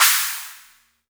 Urban Cymbal 02.wav